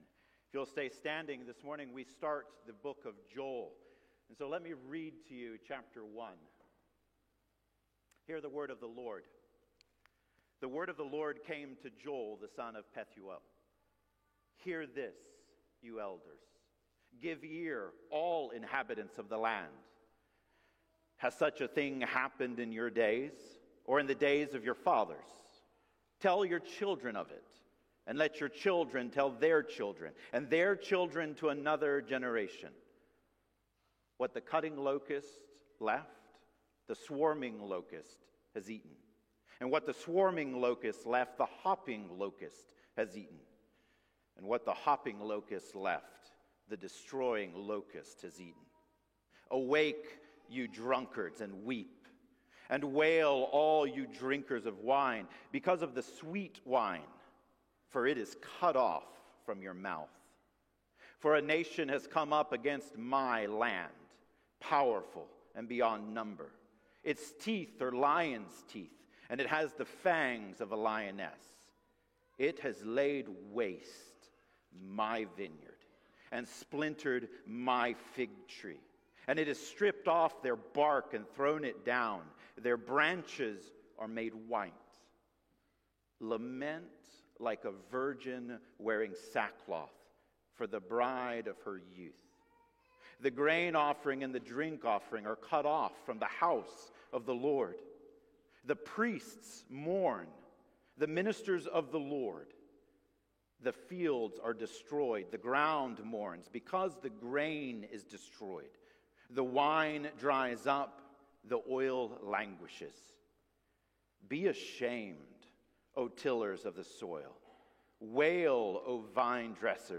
Joel 1:1-20 Service Type: Sunday Morning Download Files Bulletin « Obadiah